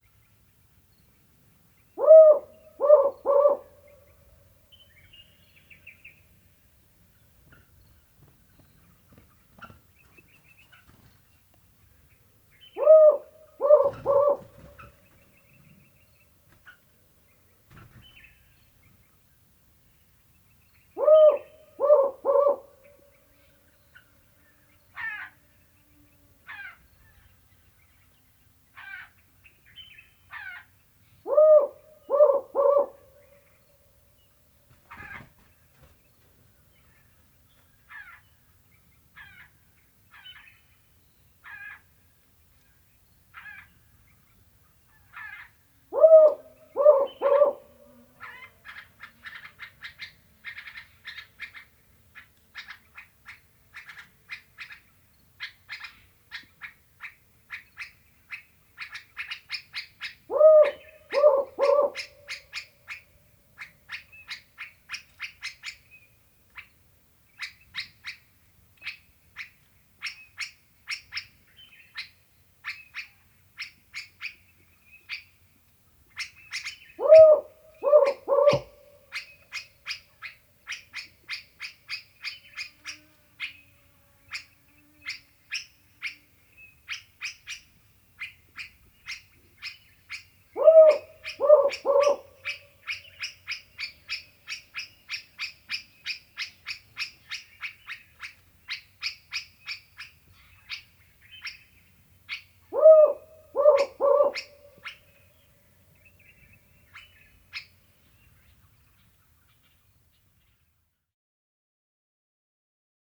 Compound hooting of a male, with White-spectacled Bulbul Pycnonotus xanthopygos arriving and mobbing it.
4-41-Humes-Owl-Compound-Hooting-Of-Male-At-Dawn-Mobbed-By-White-Spectacled-Bulbul.wav